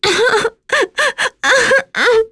Nicky-Vox_Sad.wav